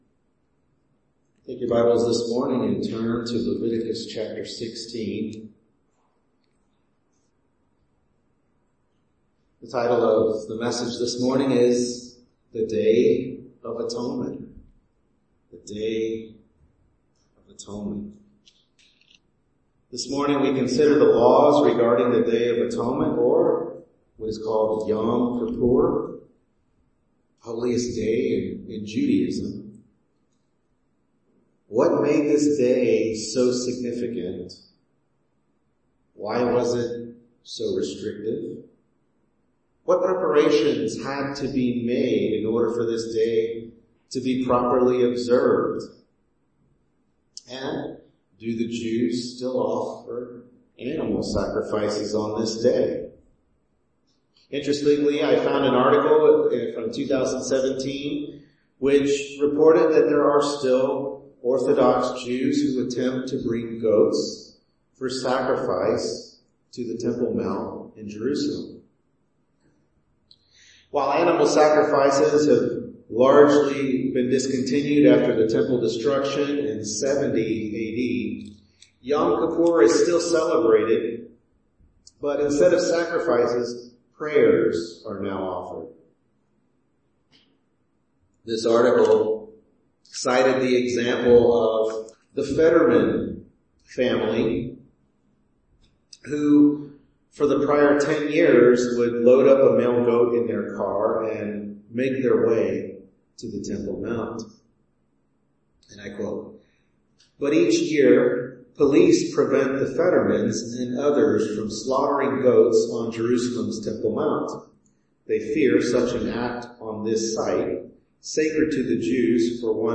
Service Type: Morning Worship Service